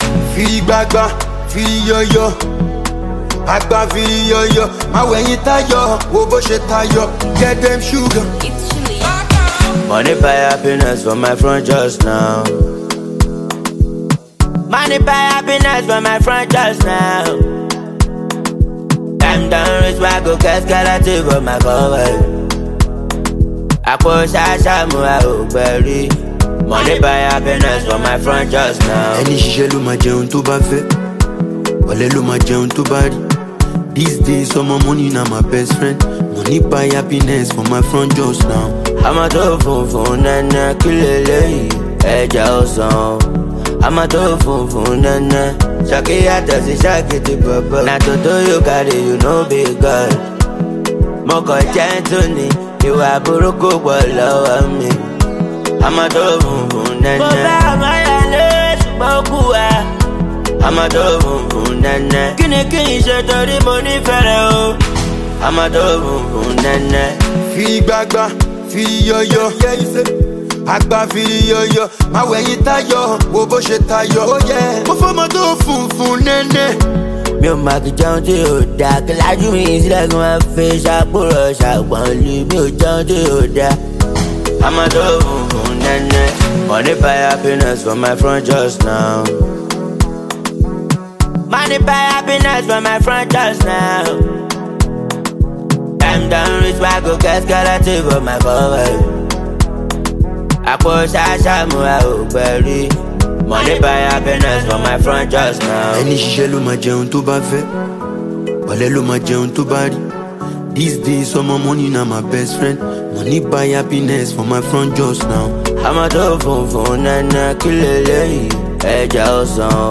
Street-hop
adding a smooth, melodic edge to the track
authentic Nigerian street music